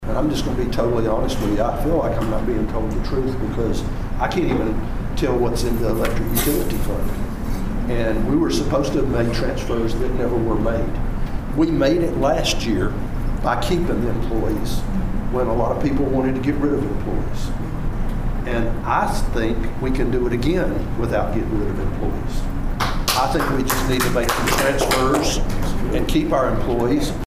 A public hearing was held on Tuesday evening at Pawhuska's community center regarding the budget for the 2025-2026 fiscal year.
Council member Mark Buchanan believes there is a way to pass a budget